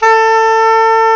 SAX SOPMFA0J.wav